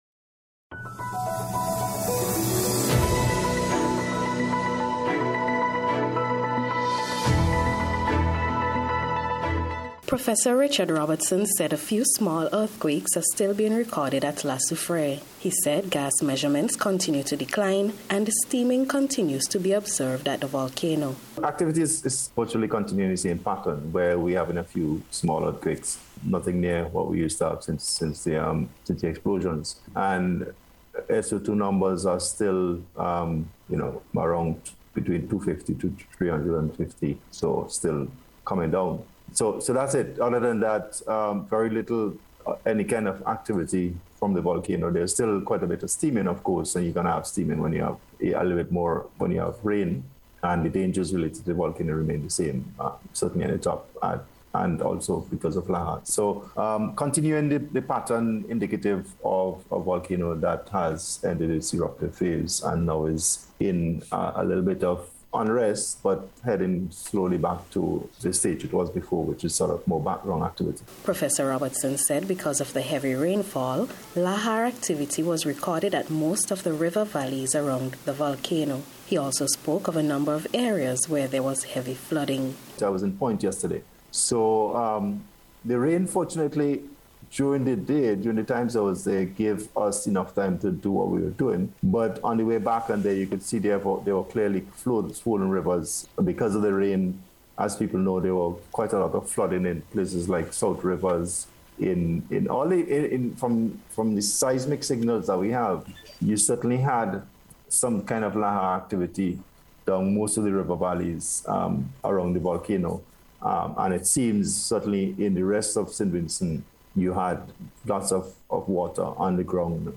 VOLCANO-SIMILAR-PATTERN-REPORT.mp3